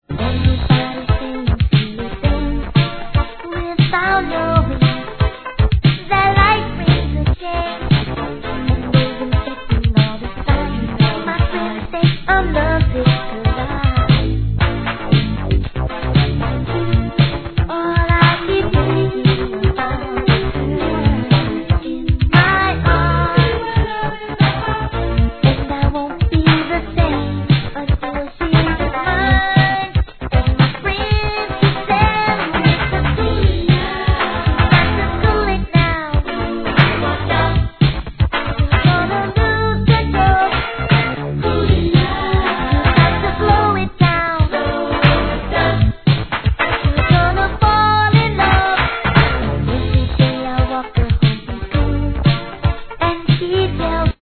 HIP HOP/R&B
'90年代の彼しか聴いていなかったのでこの幼さにびっくりします♪